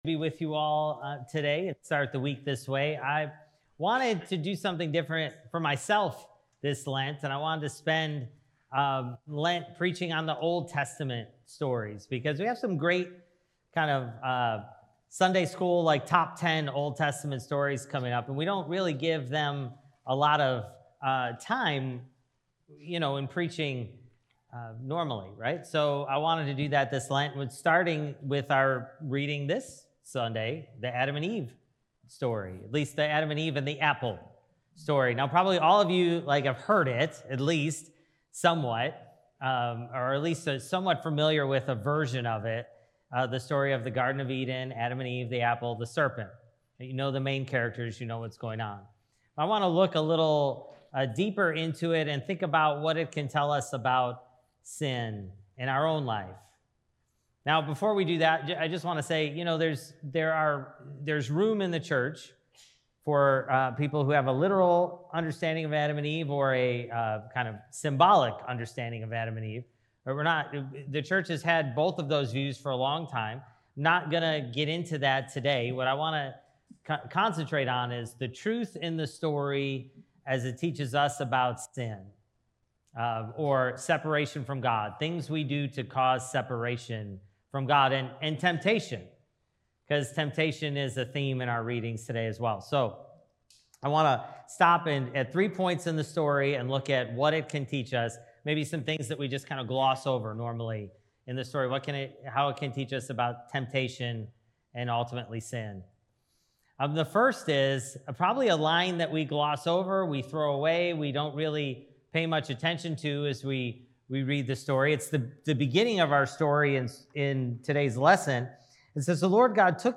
Sermons | St Alban's Episcopal Church